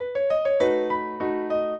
minuet8-10.wav